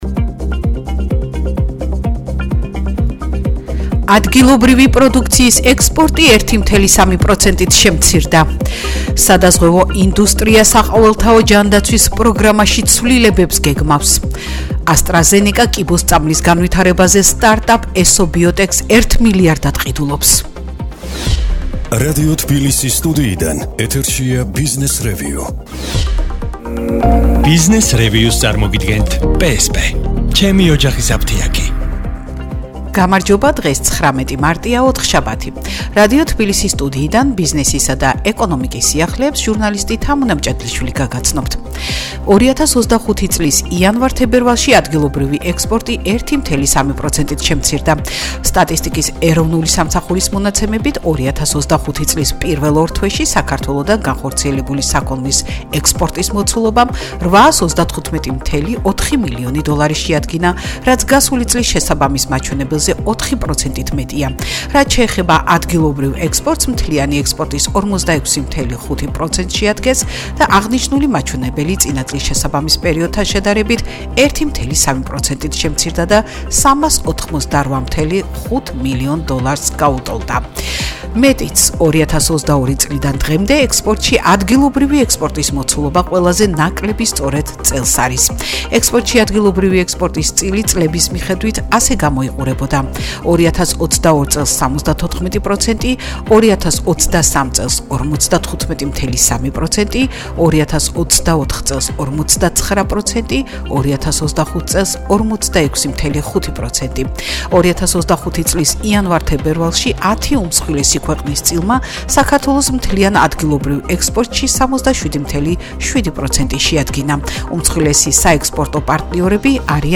ახალი ამბები ეკონომიკასა და ბიზნესზე